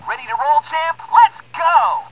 For a preview of the sound clips from the Toys R Us exclusive version, click on the three sound module bases below.